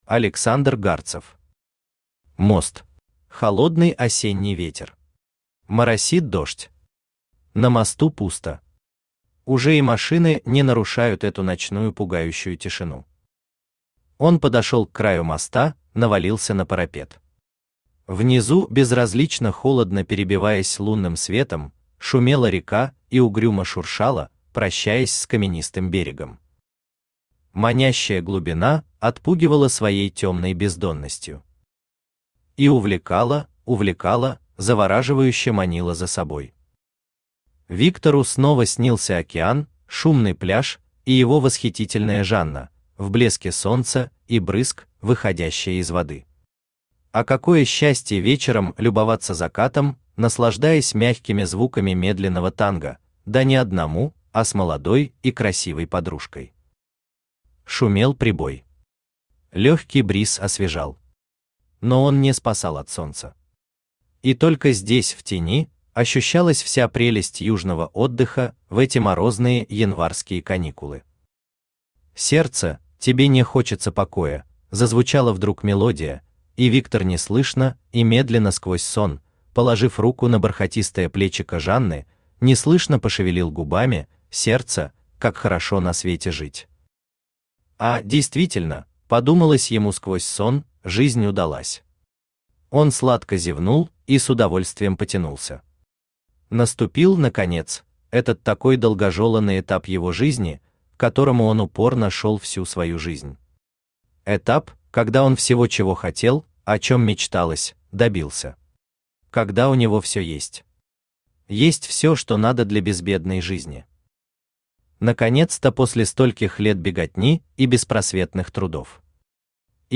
Аудиокнига Мост | Библиотека аудиокниг
Aудиокнига Мост Автор Александр Гарцев Читает аудиокнигу Авточтец ЛитРес.